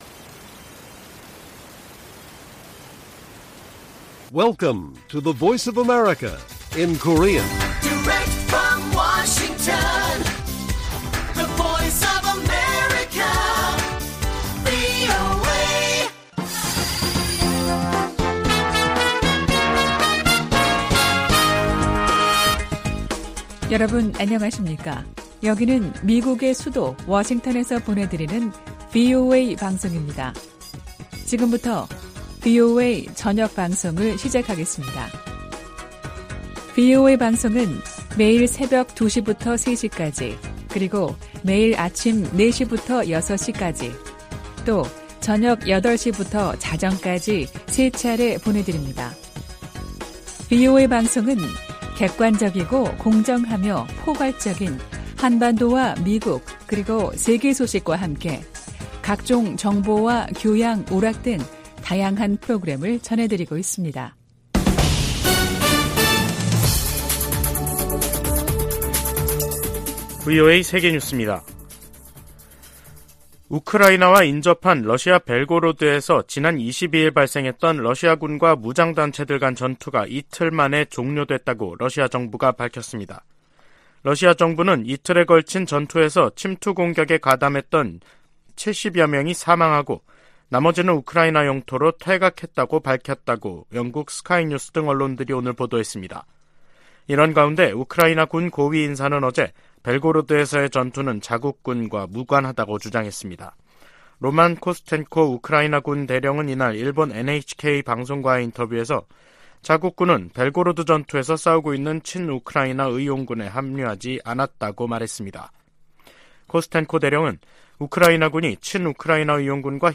VOA 한국어 간판 뉴스 프로그램 '뉴스 투데이', 2023년 5월 24일 1부 방송입니다. 미 재무부가 북한의 불법 무기 프로그램 개발에 필요한 자금 조달과 사이버 활동에 관여한 기관 4곳과 개인 1명을 제재했습니다. 북한이 27년 연속 미국의 테러 방지 노력에 협조하지 않는 나라로 지정됐습니다. 북한 핵역량 고도화로 한국에서 자체 핵무장론까지 나온 상황은 워싱턴에 경종을 울린다고 전직 백악관 고위 관리들이 말했습니다.